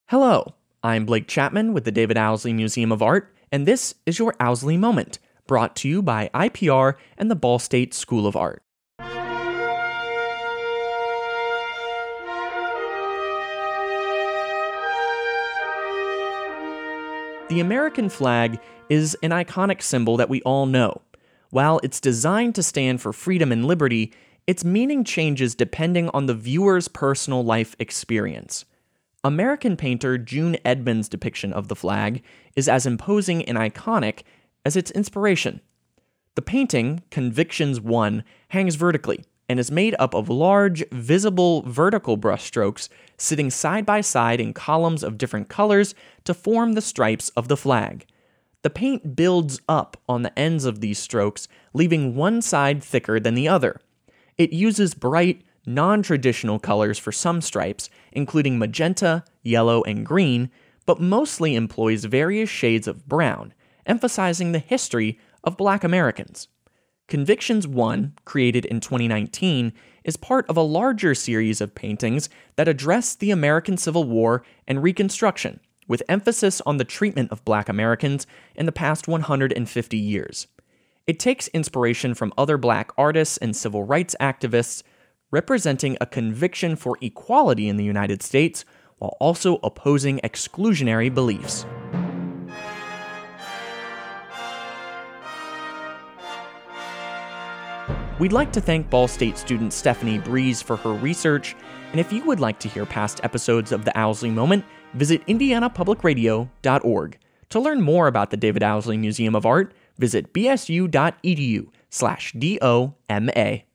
Music used in this episode:
Performed by the Cincinnati Symphony Orchestra